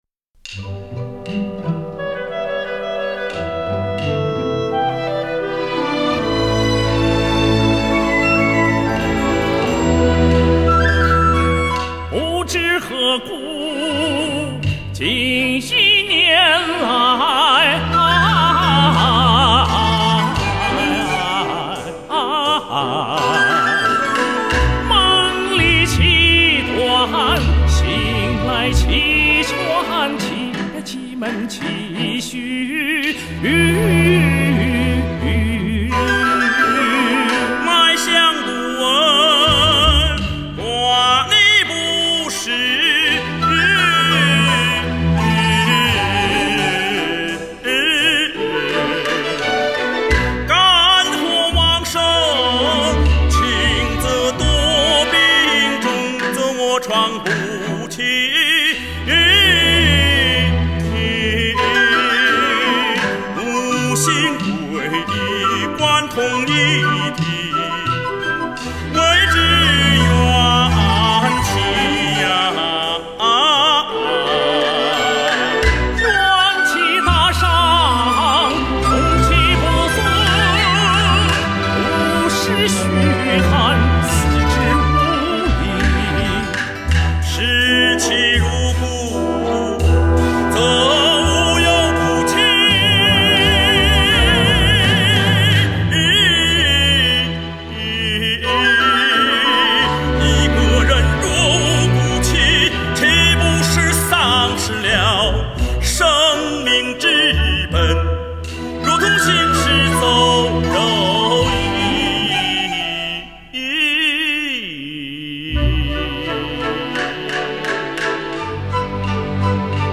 关敬陶 杨晓冬唱